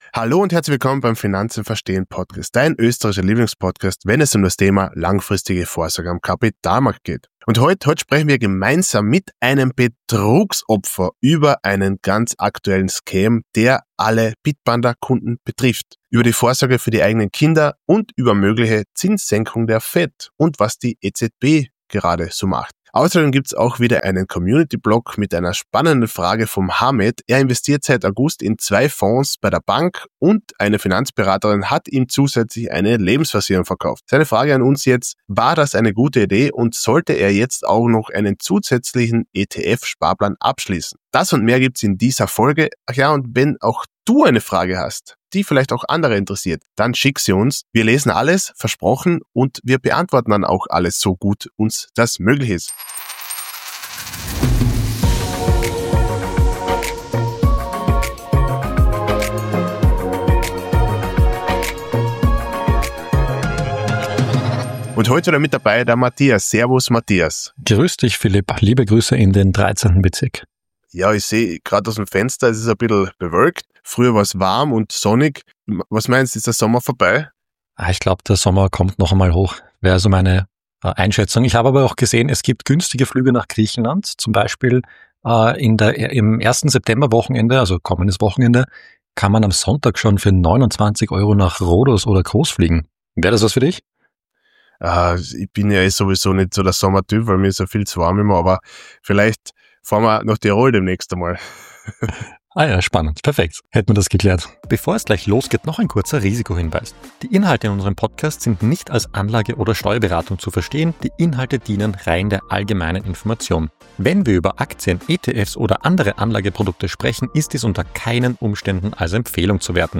In dieser Folge sprechen wir mit einem Betroffenen über einen echten Krypto-Betrug, der aktuell viele Bitpanda-Nutzer betrifft – und wie clever psychologische Tricks dabei eingesetzt wurden.